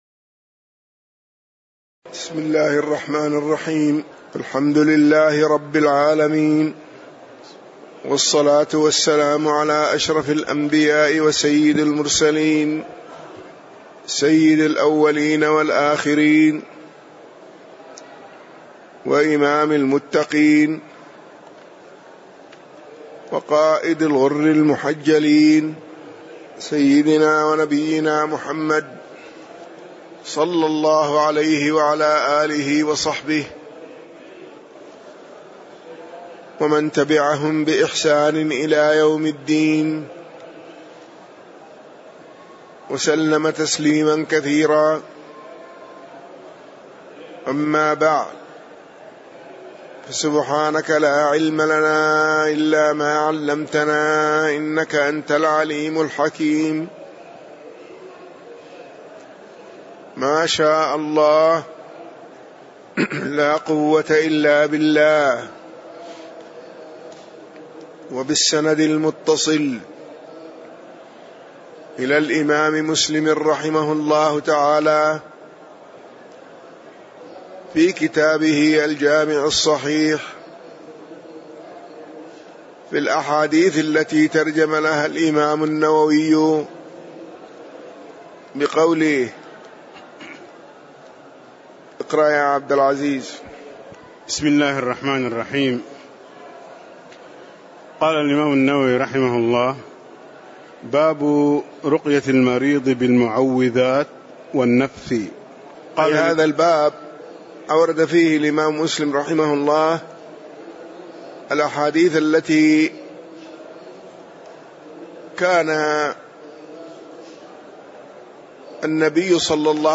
تاريخ النشر ٣ صفر ١٤٣٧ هـ المكان: المسجد النبوي الشيخ